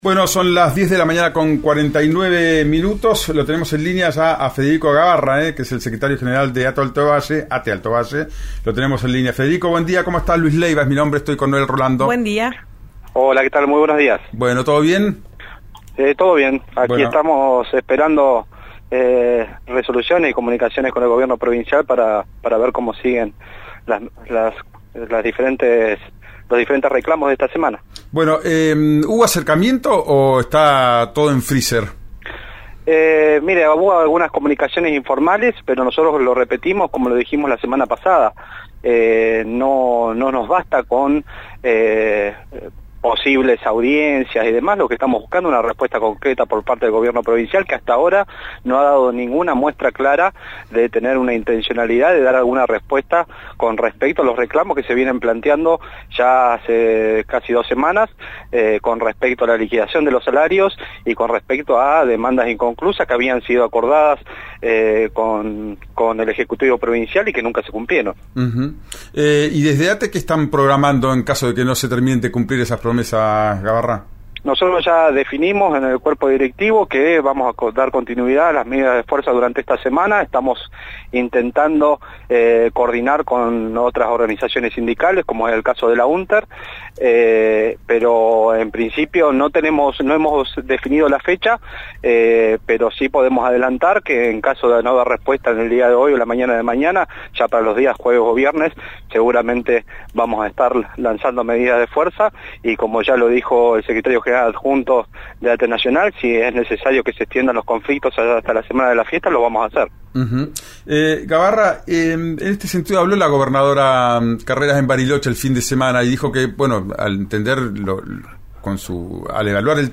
en “Ya es tiempo”, por RÍO NEGRO RADIO.